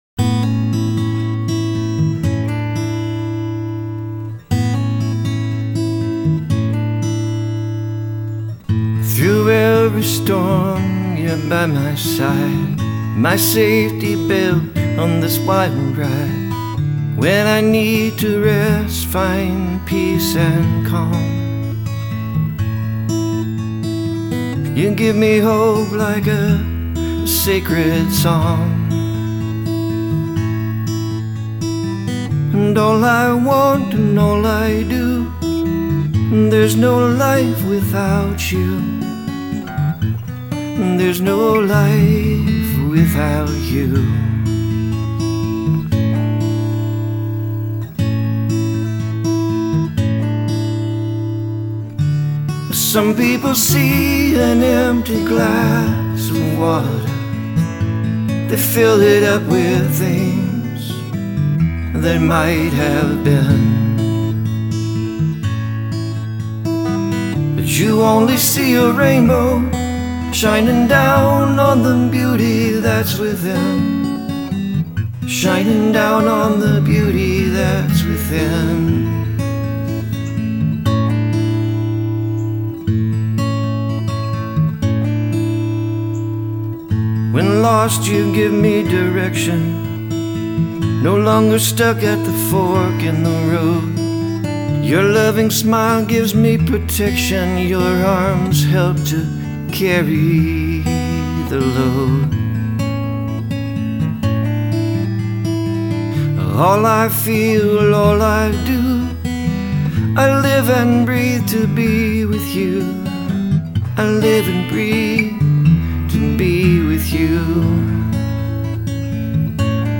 The beat is nice and slow, but not dragging, nice.
The music is sad which is a good pair with the lyrics.
• Nice acoustic sound to this song.
Guitar is smooth and soothing.